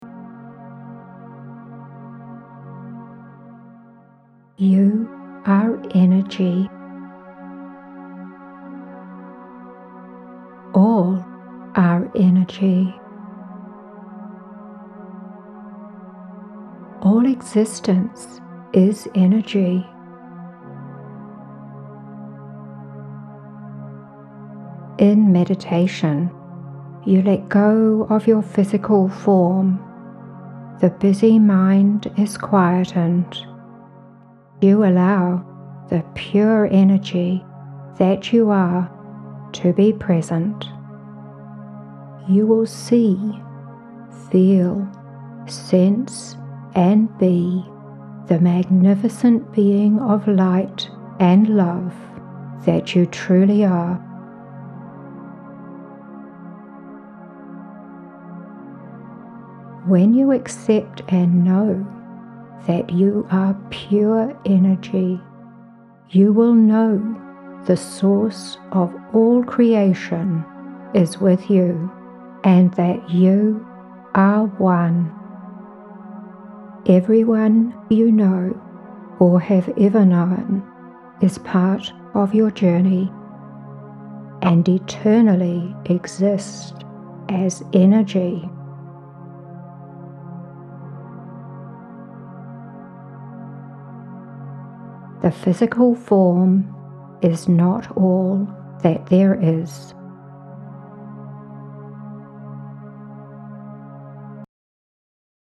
“All Is Energy” – audio (set to music) of channelled message from The Circle of The Light of The Love Energy
Posted in Audio recording, Metaphysical, Spirituality, Trance medium